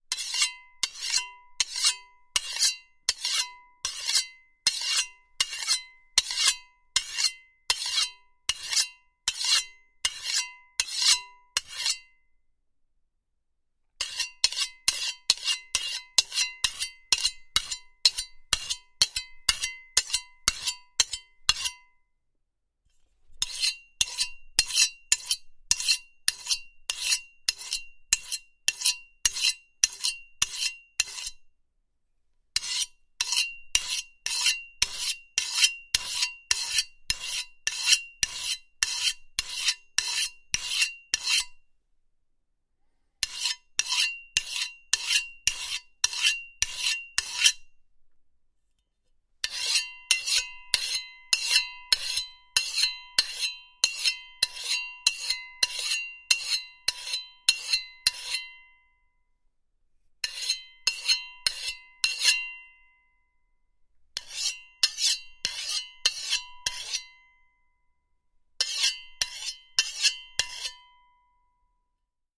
Слушайте онлайн или скачивайте бесплатно резкие, металлические скрежеты и ритмичные движения точильного камня.
Шуршание заточки ножа